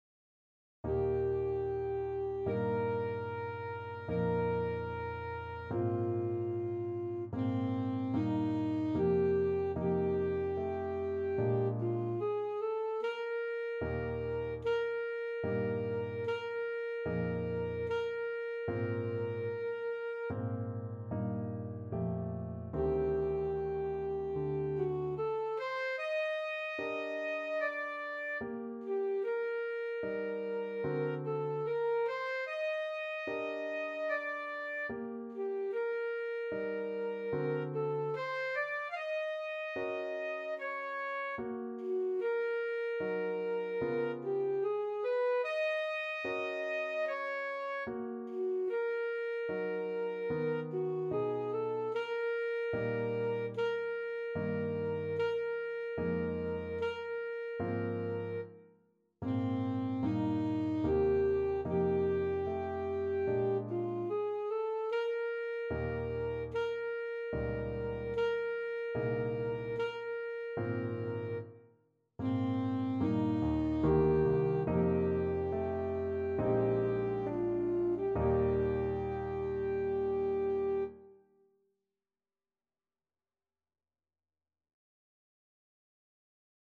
Classical Liszt, Franz Consolation No.1 in E Alto Saxophone version
Alto Saxophone
Eb major (Sounding Pitch) C major (Alto Saxophone in Eb) (View more Eb major Music for Saxophone )
Andante con moto =74 (View more music marked Andante con moto)
4/4 (View more 4/4 Music)
Classical (View more Classical Saxophone Music)